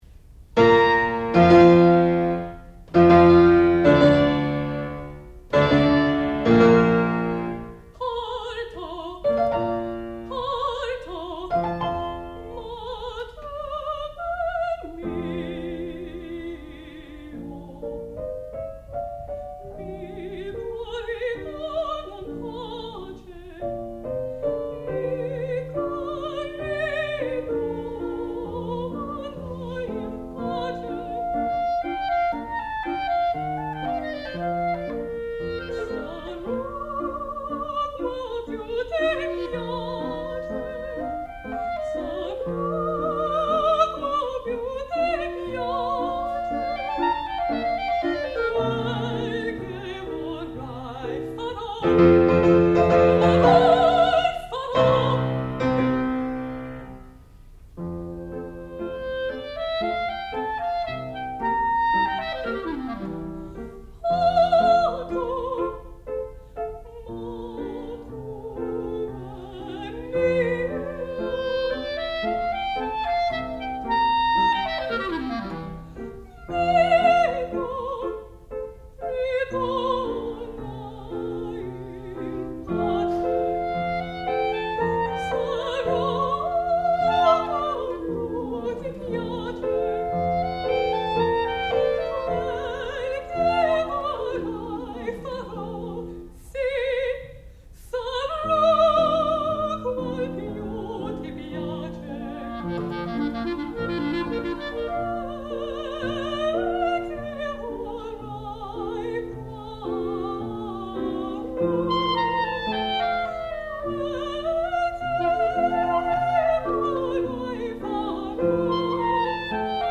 sound recording-musical
classical music
clarinet
piano
soprano
Qualifying Recital